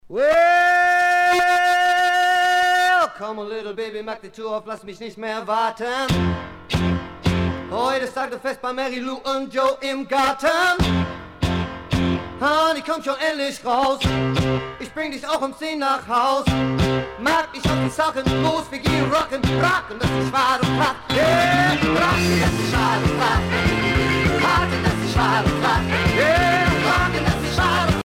danse : rock
Pièce musicale éditée